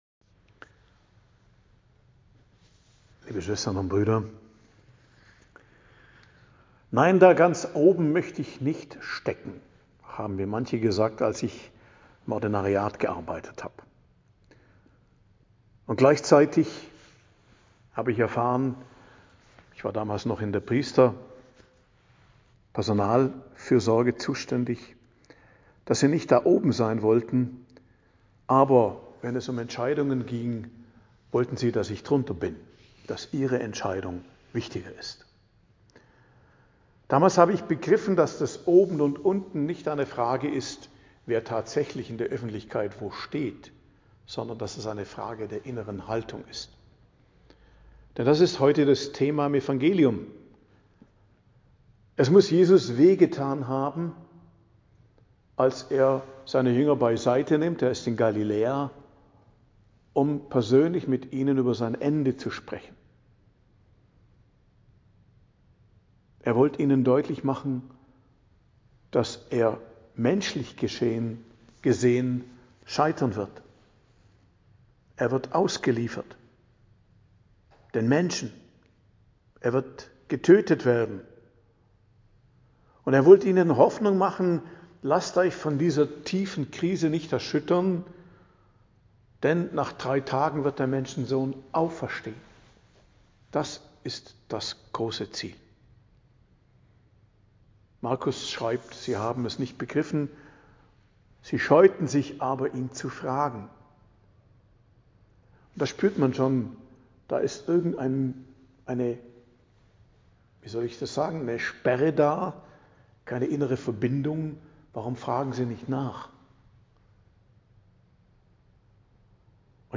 Predigt am Dienstag der 7. Woche i.J. 25.02.2025